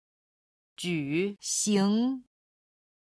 举行　(jǔ xíng)　挙行する